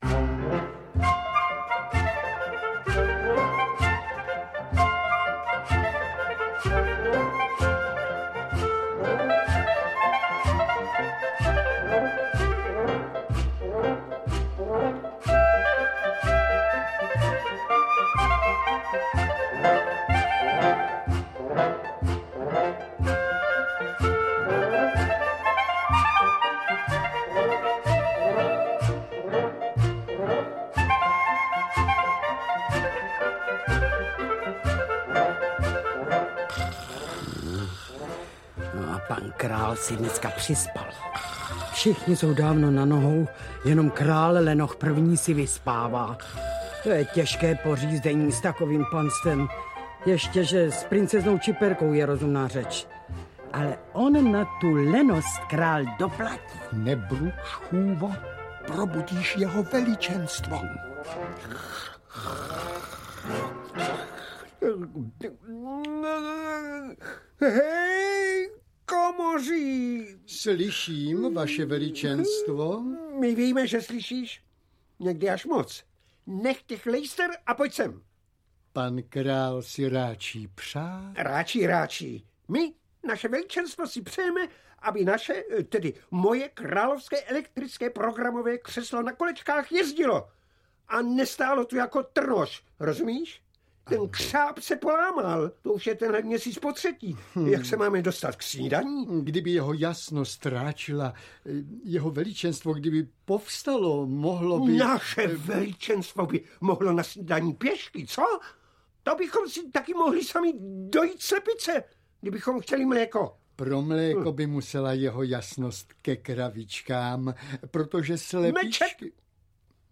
Král Lenoch a princezna Čiperka, Obr, který příliš dlouho spal, Hroch Boleslav - Jaroslav Nečas, Jan Kramařík - Audiokniha
• Čte: Svatopluk Beneš, Jana Boušková, Václav…